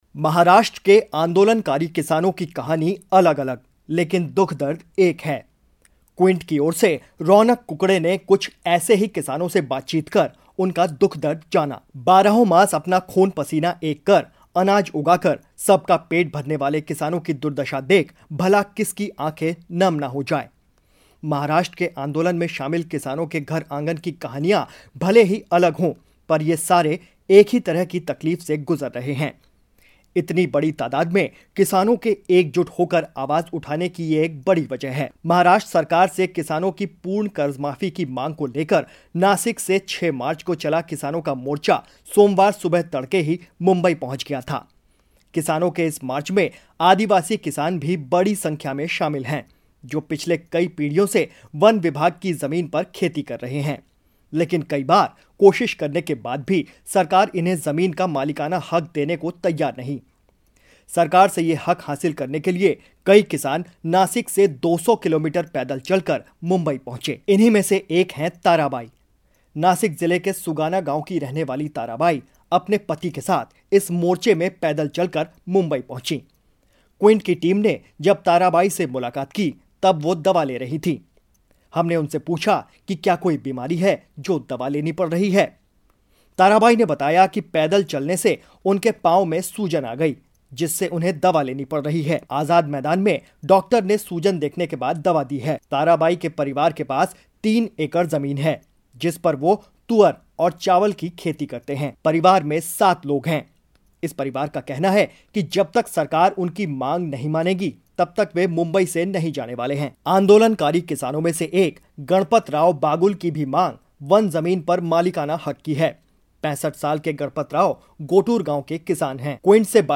क्‍विंट ने कुछ आंदोलनकारी किसानों से बातचीत कर उनका दुख-दर्द जाना. महाराष्ट्र सरकार से किसानों की पूर्ण कर्जमाफी की मांग को लेकर नासिक से 6 मार्च को चला किसानों का मोर्चा सोमवार सुबह तड़के ही मुंबई पहुंच गया था.